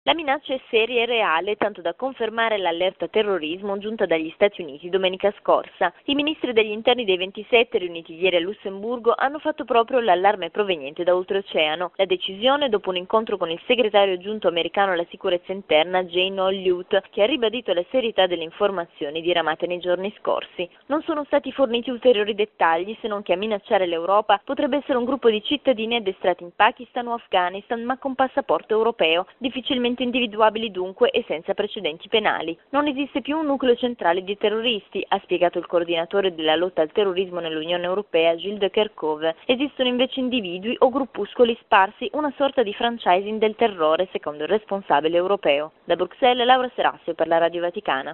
L’Europa si interroga sull’allarme terrorismo lanciato in questi giorni dagli Stati Uniti. Ieri a Lussemburgo vertice dei ministri degli Interni degli Stati membri alla presenza di un rappresentante dell’amministrazione Obama. I dettagli nel servizio